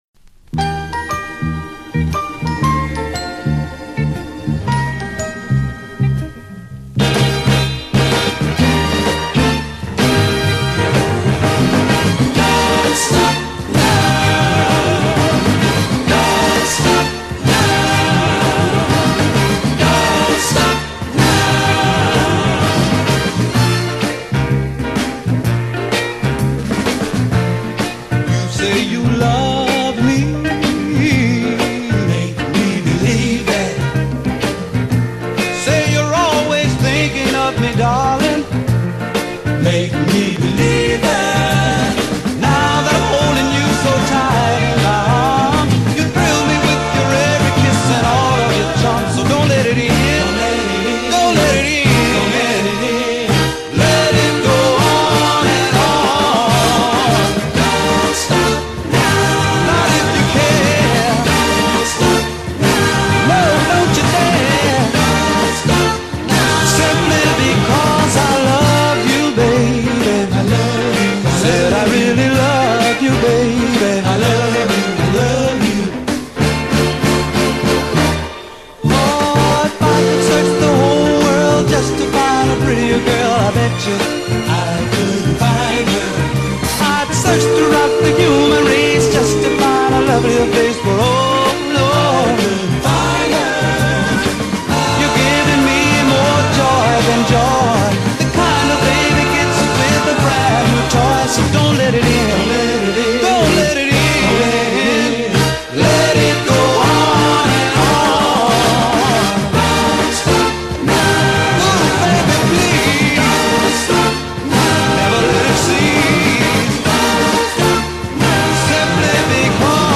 Why not browse the many other interviews on this site and get to know the back stories to the artists you know and love